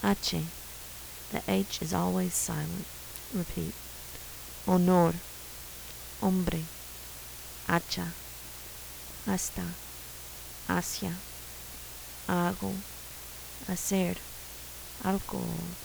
Sounds that are pretty different from English
Sound like h            silent                       sounds like h           sounds like y           sounds like ny